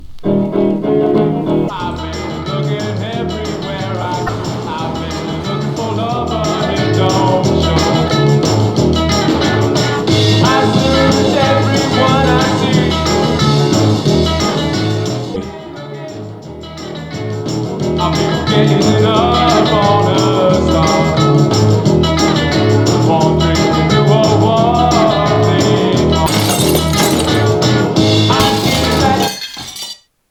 Напоминает Роллингов.
Да, тоже заметил, что голос похож на Мика Джаггера.